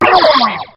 Line of Bubbler in Diddy Kong Racing.
Bubbler_(hit).oga.mp3